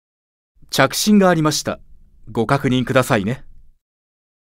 File:Barbatos Call Notification Voice.ogg
Barbatos_Call_Notification_Voice.ogg.mp3